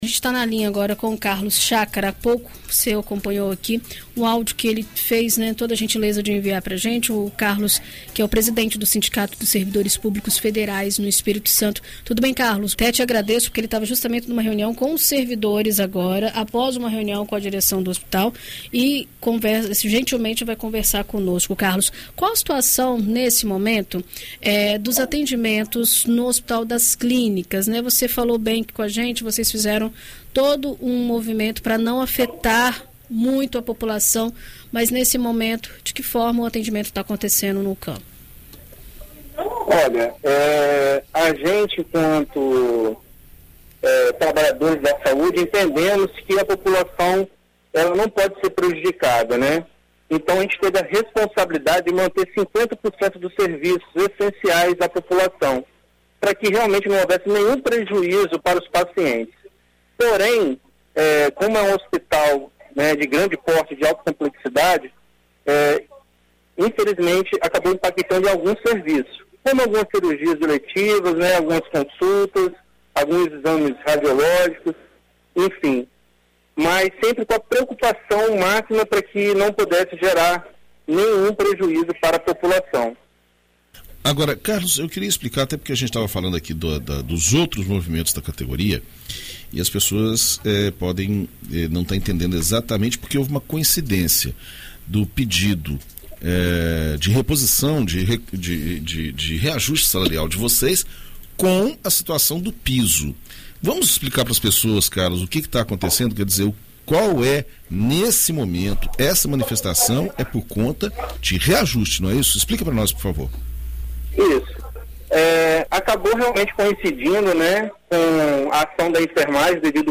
Em entrevista à BandNews FM Espírito Santo nesta quarta-feira (21)